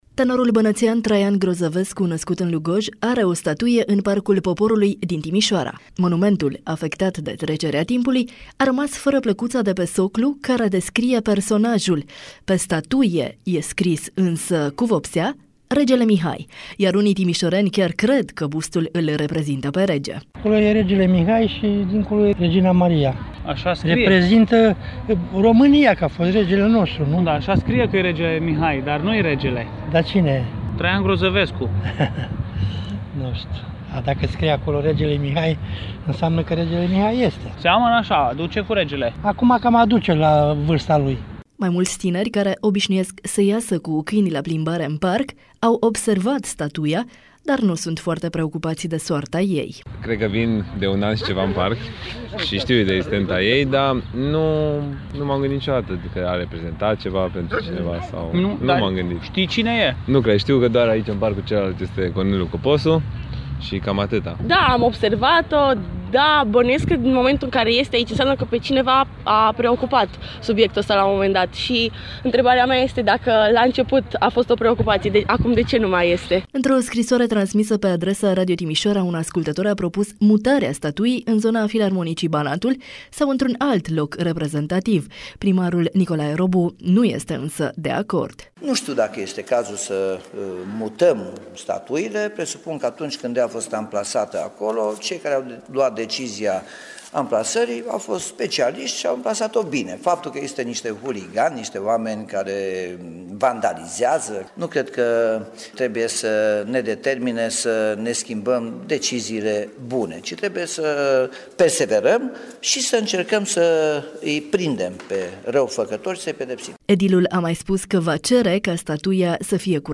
Mai mulți tineri ieșiți cu câinii la plimbare în parc au observat statuia, dar nu sunt foarte preocupati de soarta ei:
Primarul Nicolae Robu nu este de acord cu mutarea: